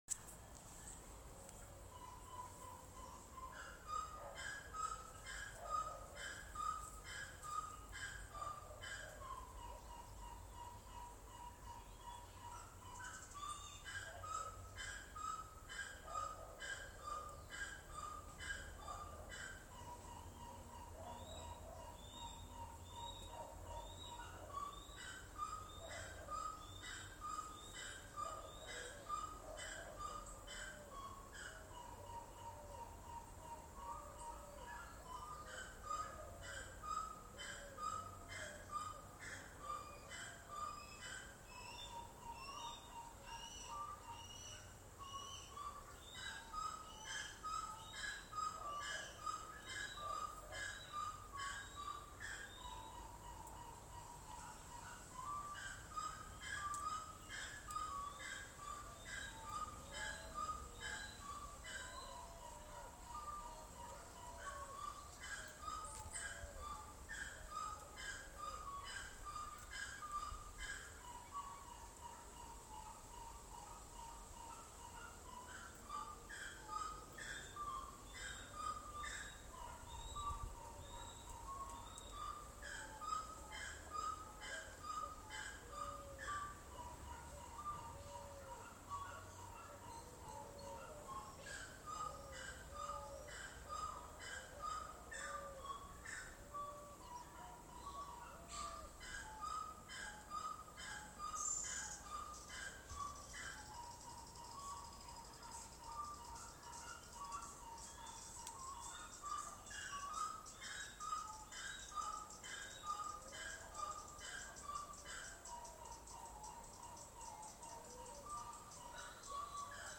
Chiricote (Aramides cajaneus)
Nombre en inglés: Grey-cowled Wood Rail
Localidad o área protegida: Mar de Cobo
Condición: Silvestre
Certeza: Fotografiada, Vocalización Grabada